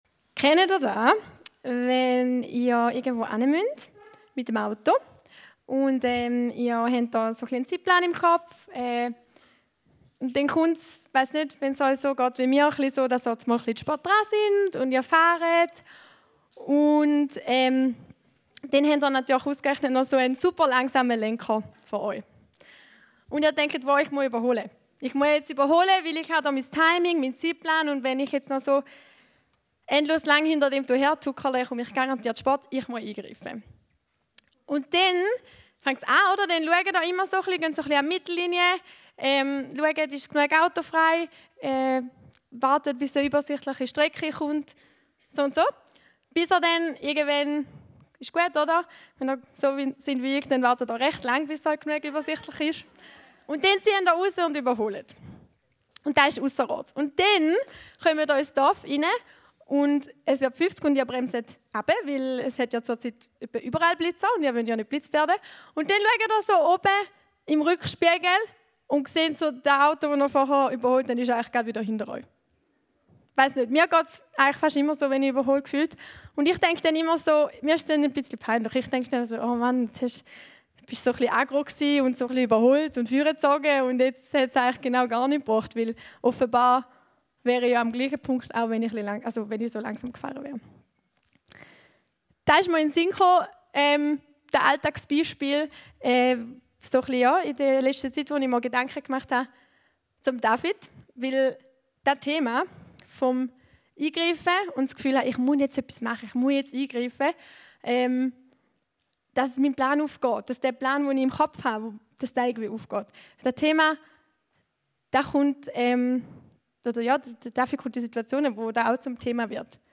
Eine predigt aus der serie "RISE & FALL." Freundschaft ist kein Bonus – sie ist lebensnotwendig. In dieser Predigt tauchen wir ein in die bewegende Geschichte von David und Jonathan und entdecken, was echte Freundschaft ausmacht: Commitment, Risiko, geistliche Tiefe und Liebe.